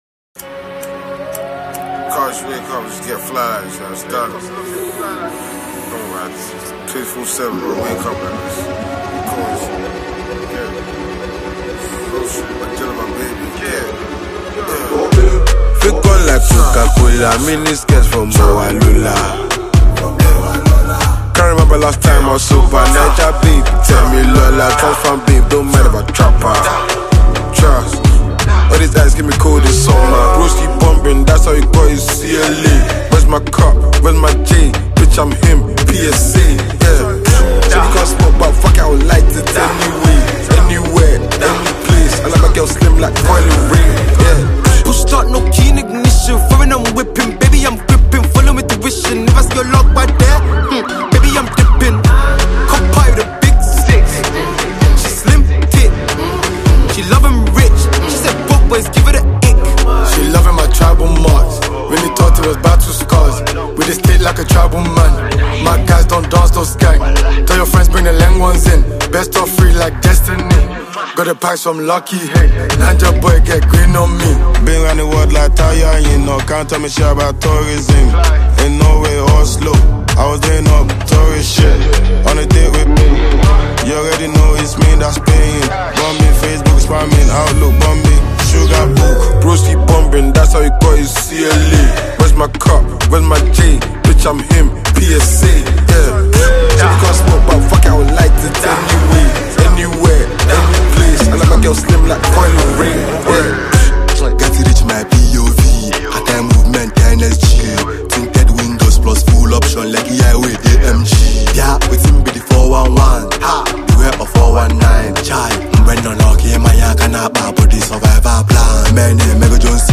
afroswing
a well-known indigenous rapper and composer from Nigeria